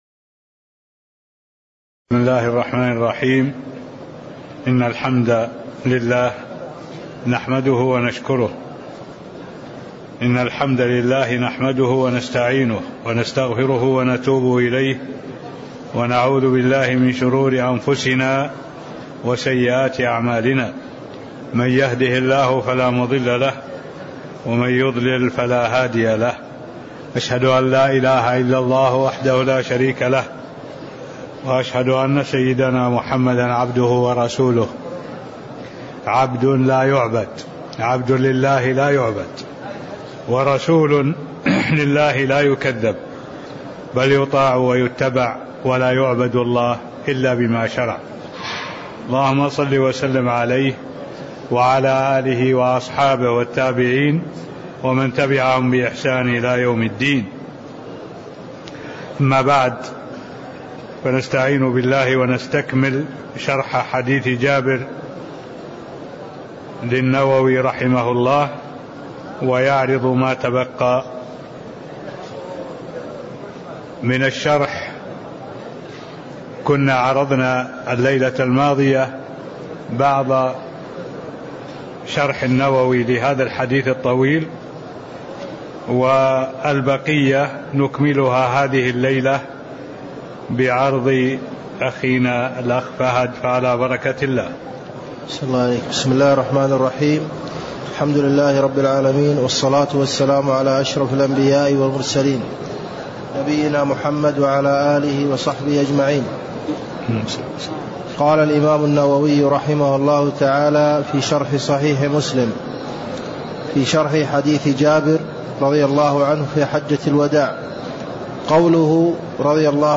المكان: المسجد النبوي الشيخ: معالي الشيخ الدكتور صالح بن عبد الله العبود معالي الشيخ الدكتور صالح بن عبد الله العبود تكملة حديث جابر في حجة الوداع (07)